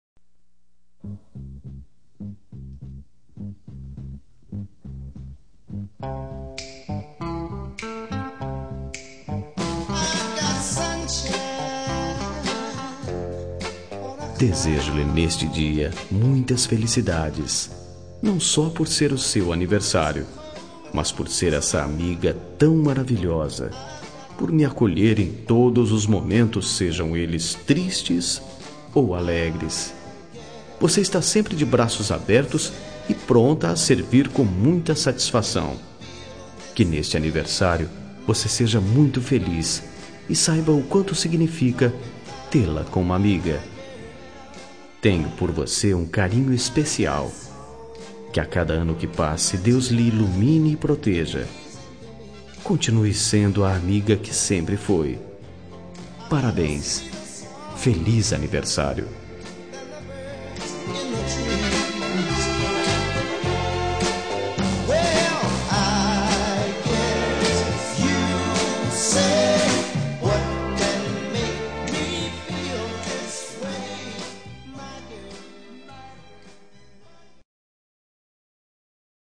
Telemensagem Aniversário de Amiga – Voz Masculina – Cód: 1577